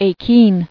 [a·kene]